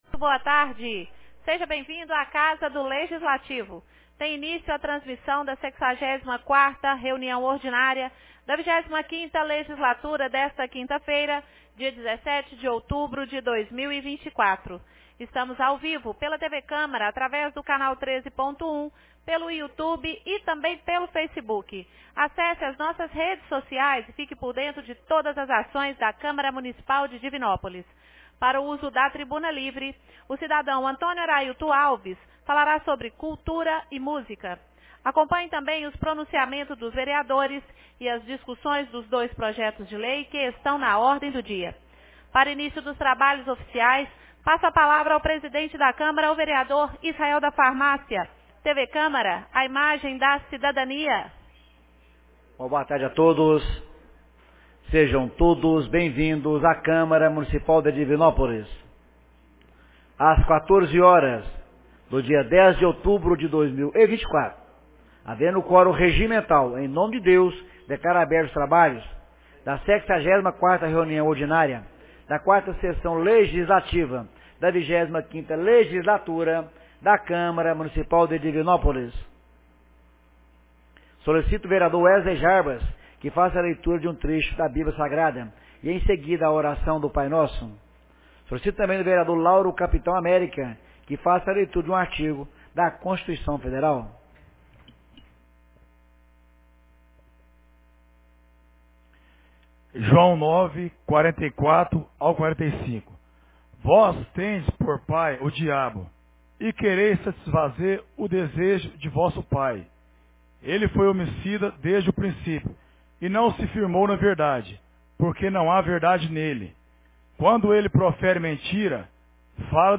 64ª Reunião Ordinária 17 de outubro de 2024